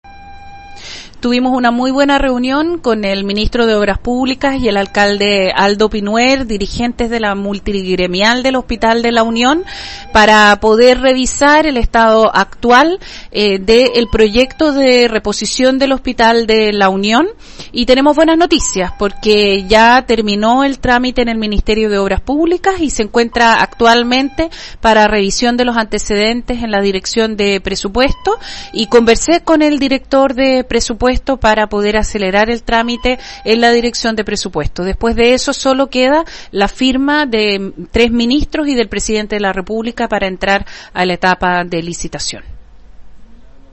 Expreso la Senadora  Ena Von Baer desde el congreso en Valparaíso